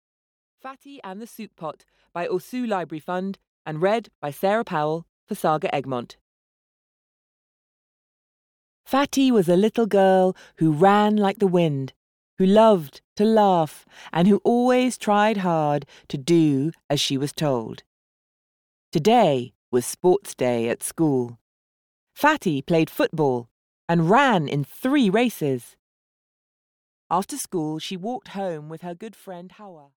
Fati and the Soup Pot (EN) audiokniha
Ukázka z knihy